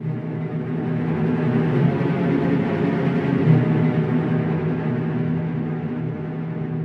调音奥卡斯特拉
描述：小型弦乐团的调音。第一把小提琴开始拉A，然后每个部分从这个开始调As，首先是低音提琴，然后是大提琴，最后是中提琴和小提琴一起。最后，乐团的所有成员都对所有的弦进行调音。
Tag: 弦乐队 调音 小提琴